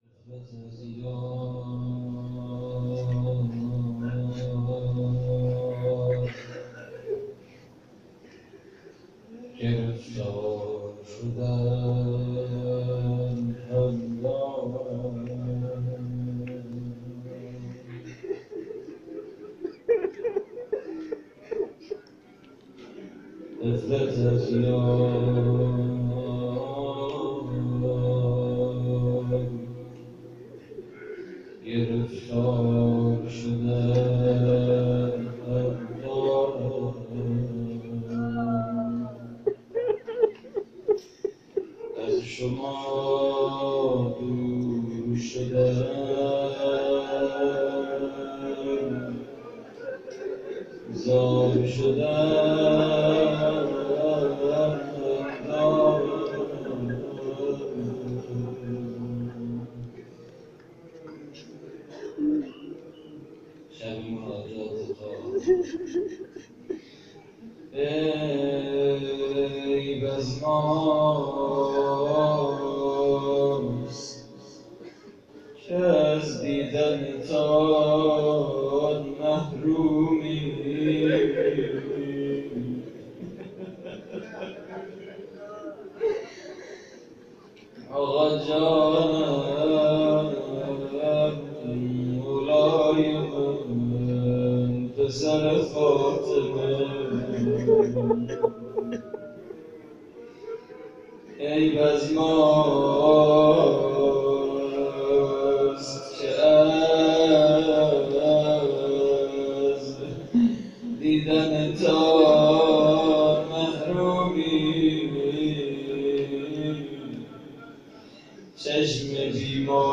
مناجات: غفلت از یار گرفتار شدن هم دارد
احیای نیمه شعبان / هیئت رهپویان آل طاها (ع) - مسجد لولاگر؛